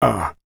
Male_Grunt_Hit_03.wav